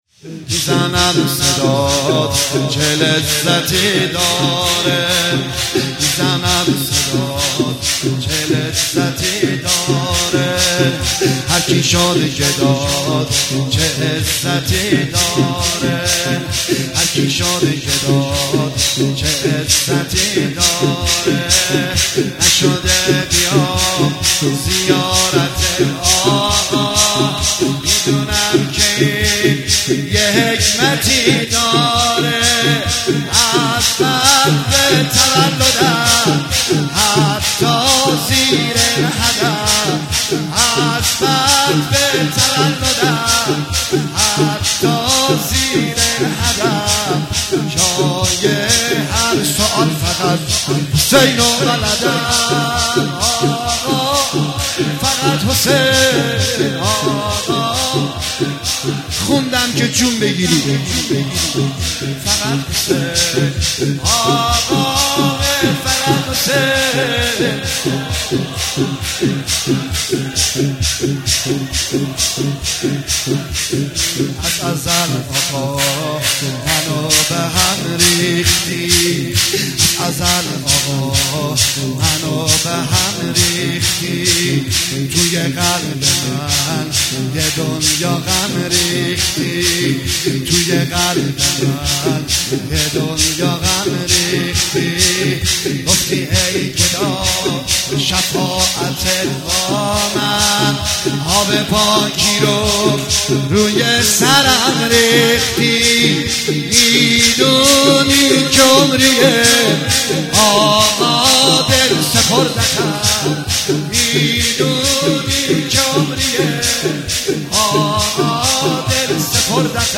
مناسبت : شب هشتم محرم
قالب : شور